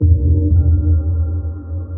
sonarPingAirFarShuttle1.ogg